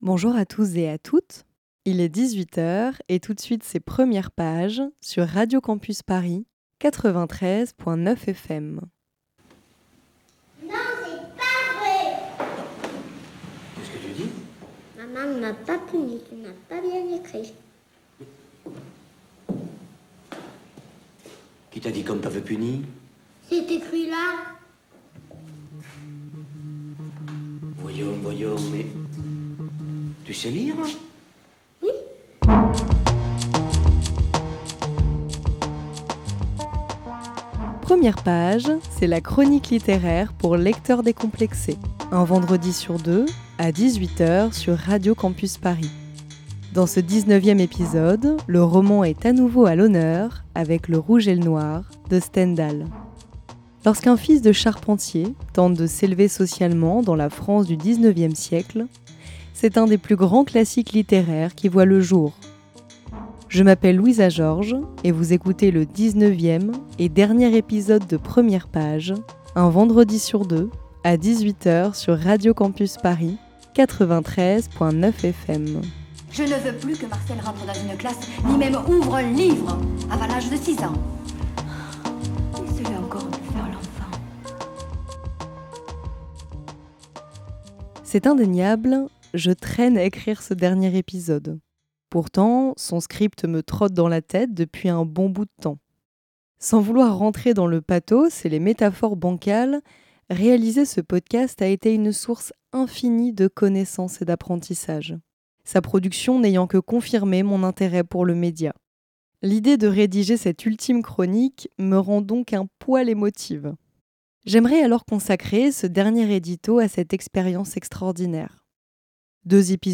Voici en podcast le dix-neuvième et dernier épisode de Premières pages, une chronique littéraire pour lecteur.trice.s décompléxé.e.s un vendredi sur deux sur Radio campus Paris.